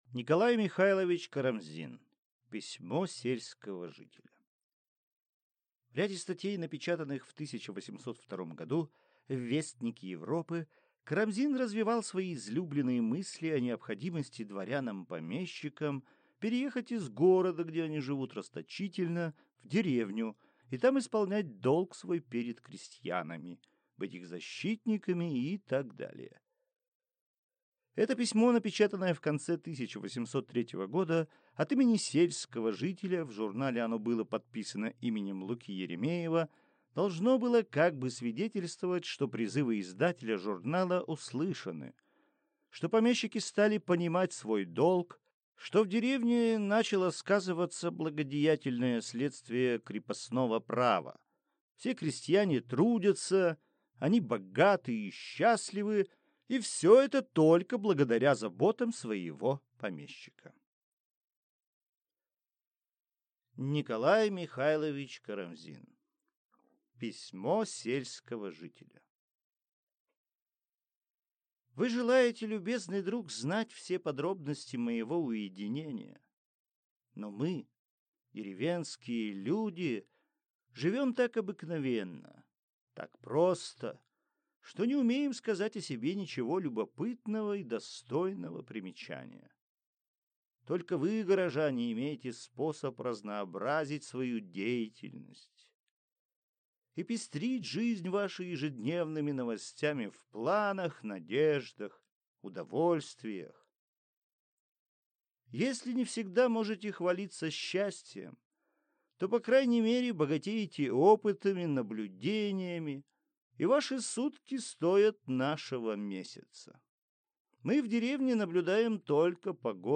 Аудиокнига Письмо сельского жителя | Библиотека аудиокниг